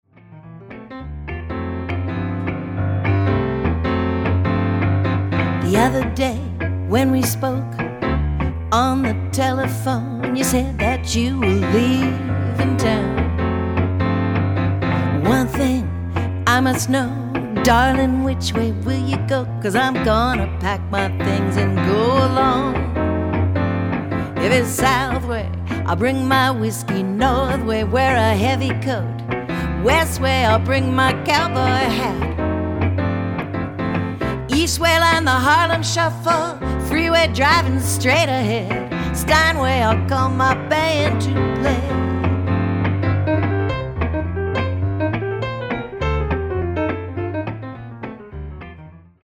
powerful voice
playful jazz style